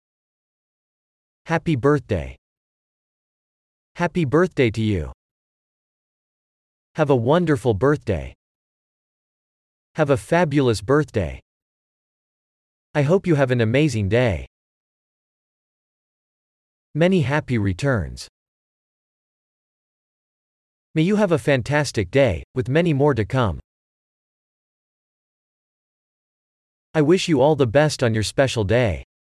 Et voici un MP3 de ces vœux d’anniversaire par un anglophone pour la prononciation correcte: